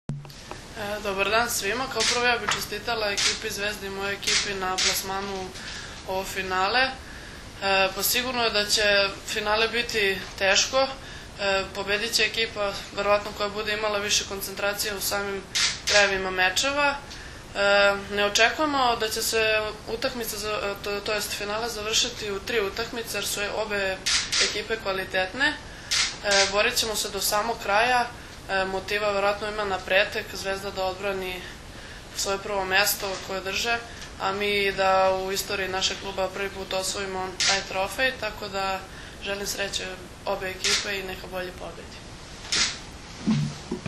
Tim povodom, danas je u prostorijama Odbojkaškog saveza Srbije održana konferencija za novinare